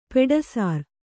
♪ peḍasār